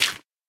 gravel4.ogg